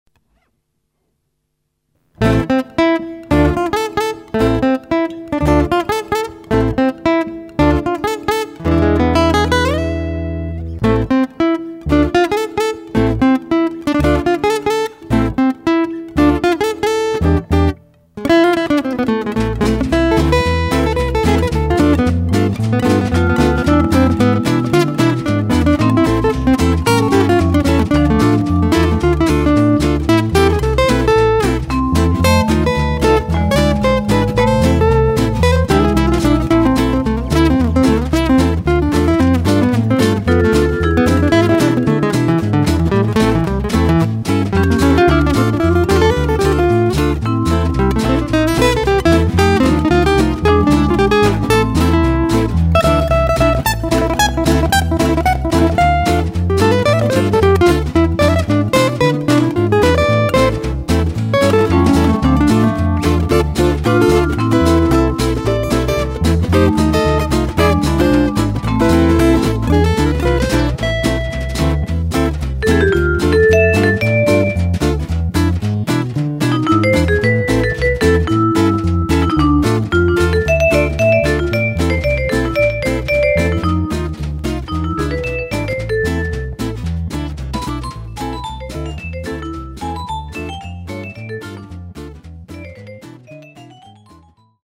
Chitarre e Basso
Pianoforte e Vibrafono
Batteria